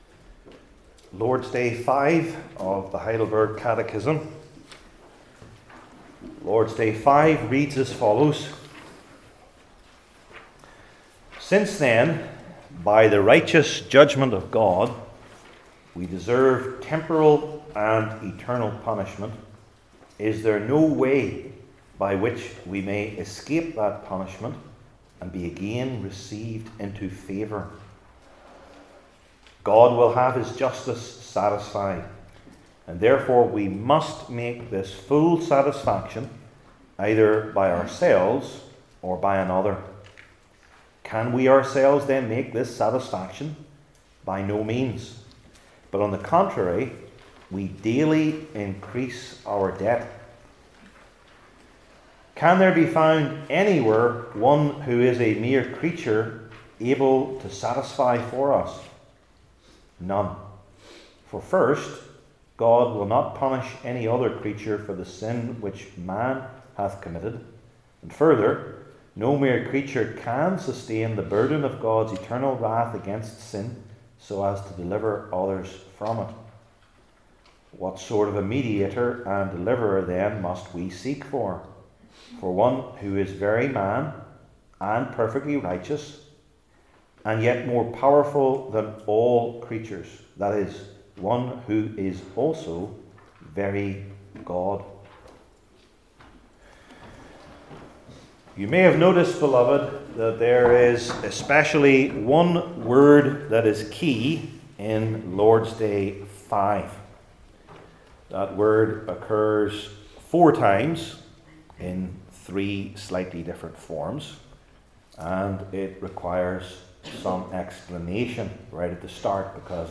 Deuteronomy 27:11-26 Service Type: Heidelberg Catechism Sermons I. The Terrible Need for Satisfaction II.